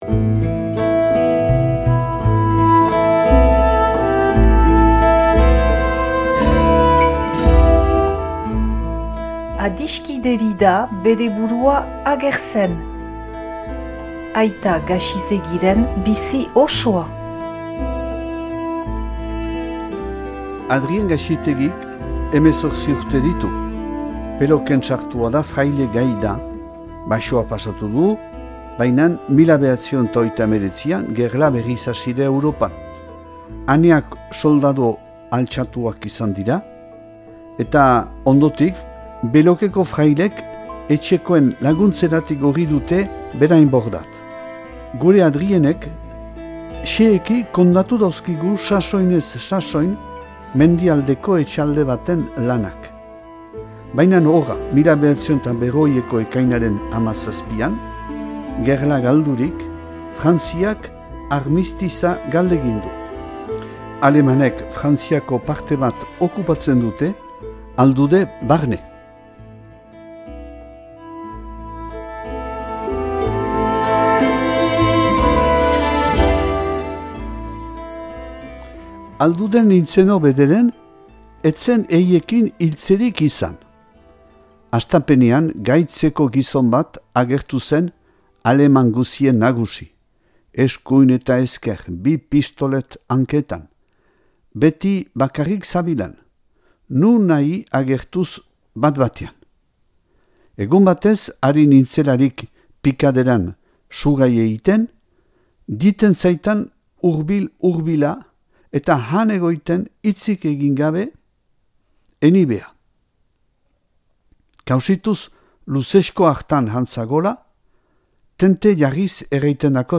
irakurketa bat dauzuegu eskaintzen.